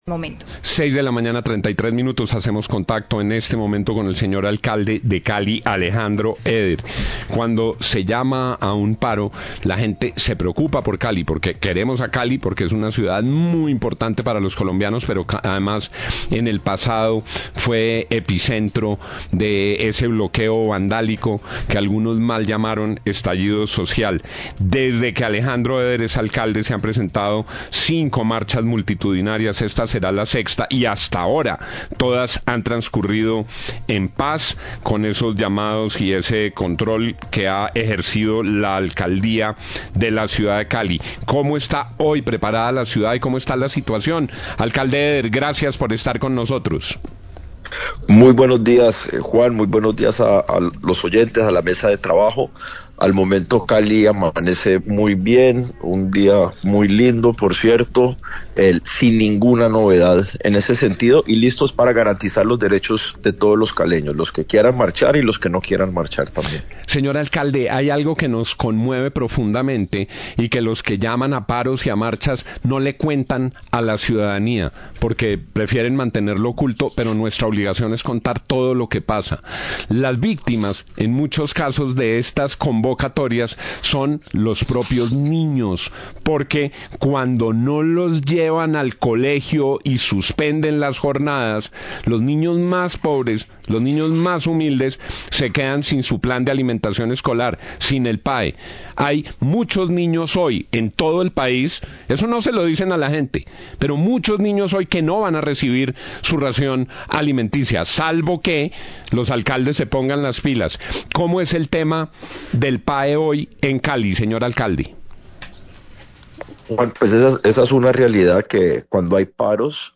Radio
Entrevista al alcalde de Cali, Alejandro Eder, sobre la situación en Cali de cara a la jornada de marchas convocadas por el gobierno nacional.